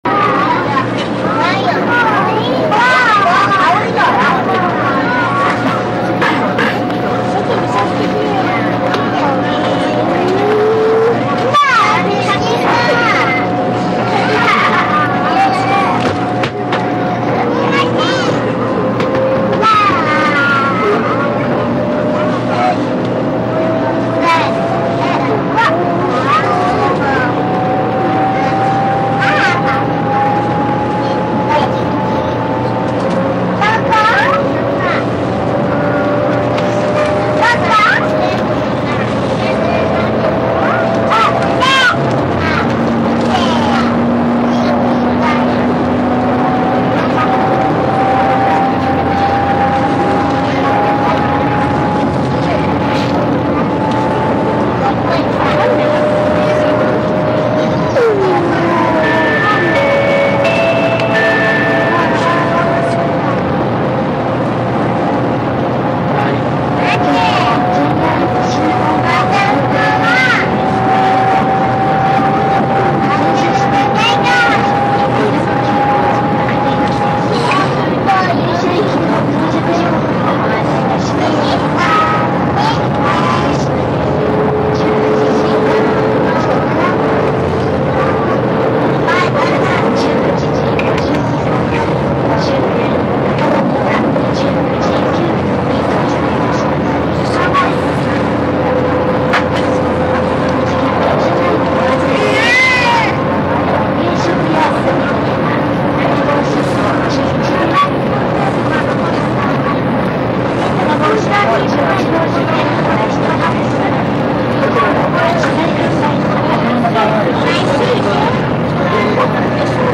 臨のぞみ509号 9509A 山陽幹 TEC500 500系がまだ山陽内のみだった頃の多客臨。W1編成。
新大阪発車から5分あまりの間。